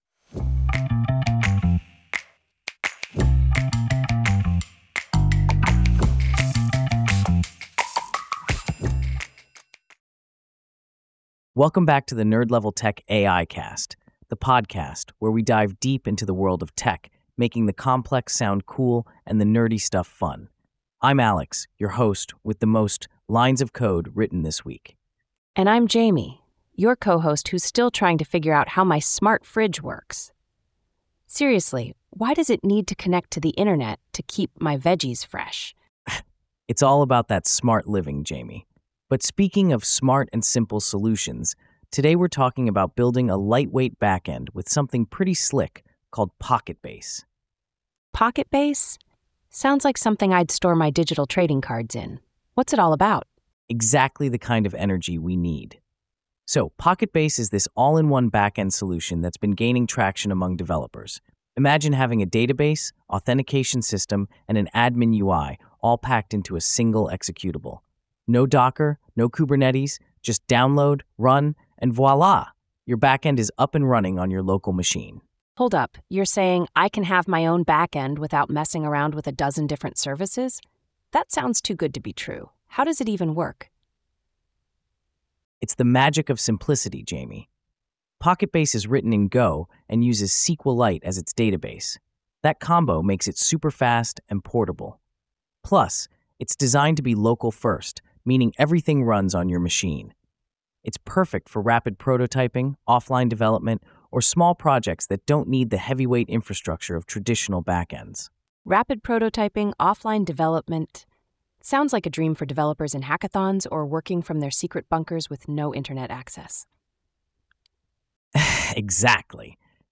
AI-generated discussion